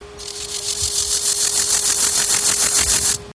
Акустические сигналы: одиночный самец, Россия, Иркутск, запись
Температура записи 26-28° С.